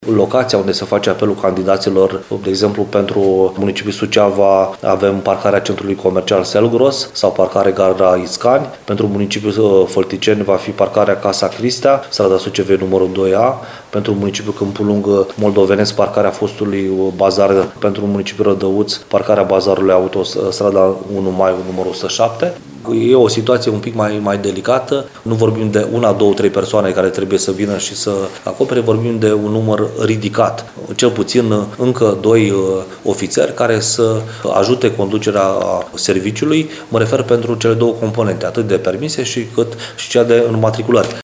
Referindu-se la noutățile pentru candidați, prefectul ALEXANDRU MOLDOVAN a declarat astăzi că participanții la proba practică vor avea noi puncte de convocare la examen.